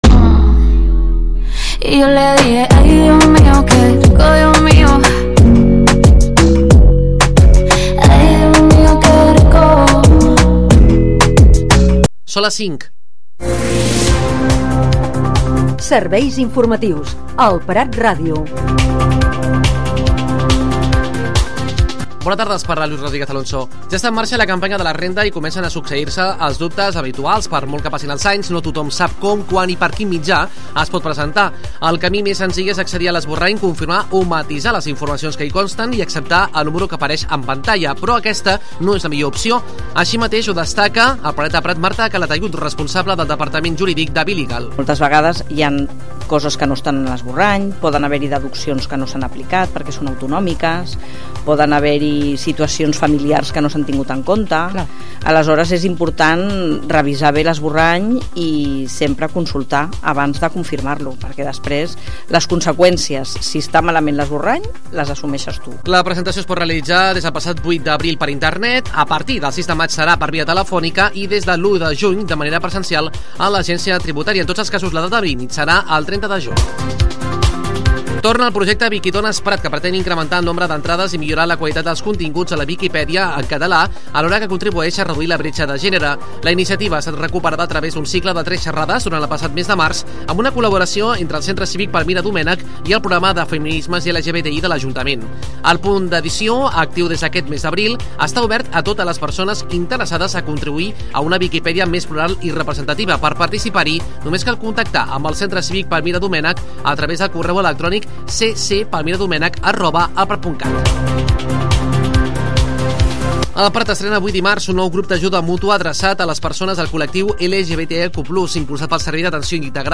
Butlletí de les 17:00 h